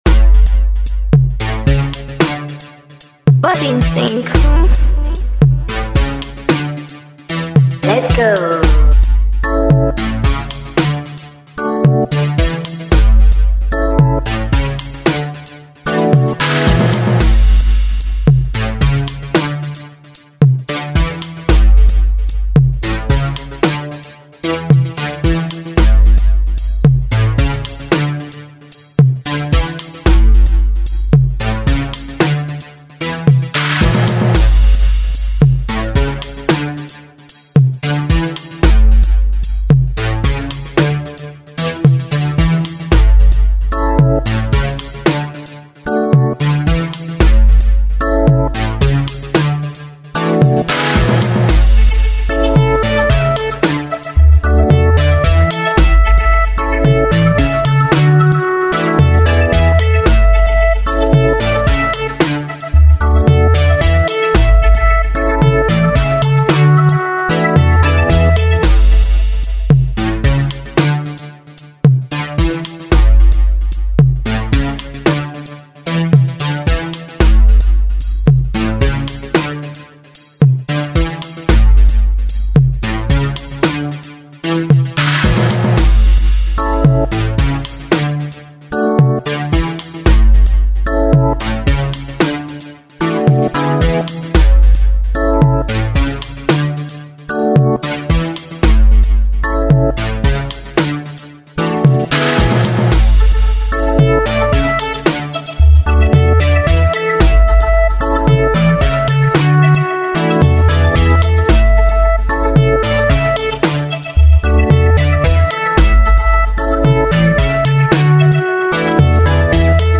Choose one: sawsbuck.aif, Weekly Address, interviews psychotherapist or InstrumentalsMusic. InstrumentalsMusic